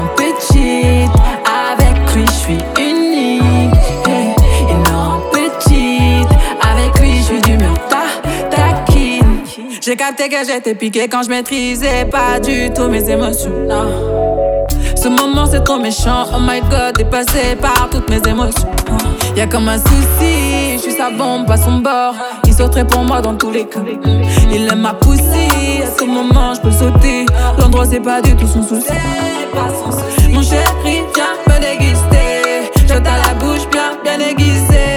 Afro-Pop
Жанр: Поп музыка